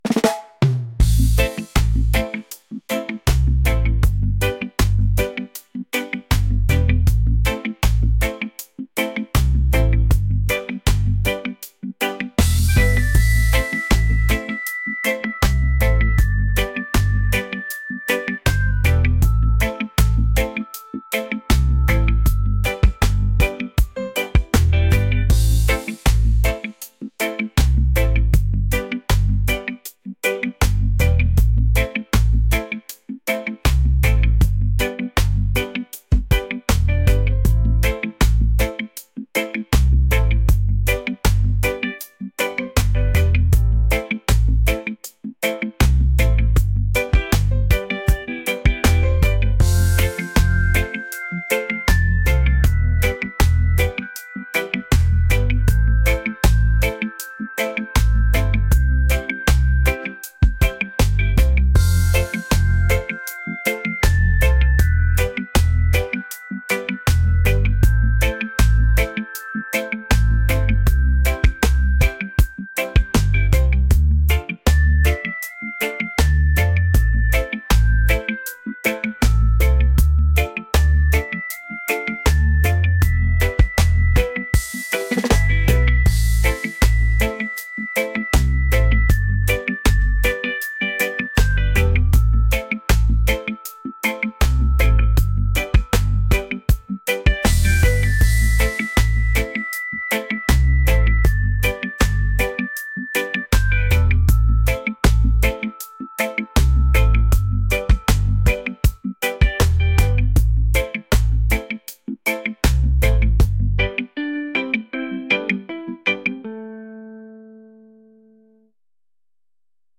reggae | laid-back | soulful